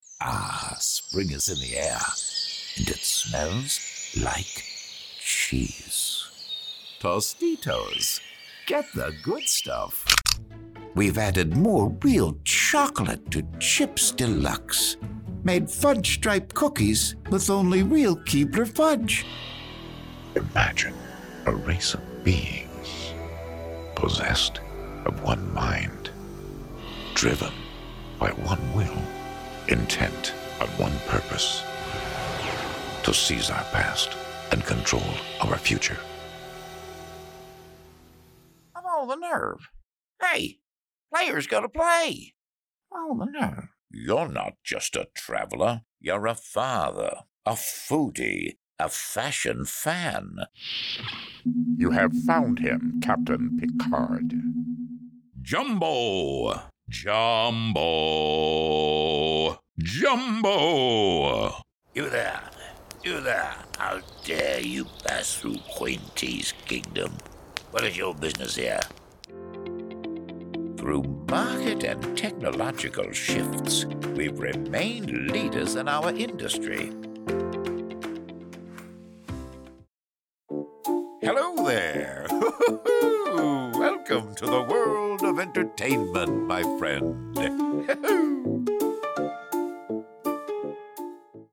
Versatile, Powerful, Directable
Character Demo
Middle Aged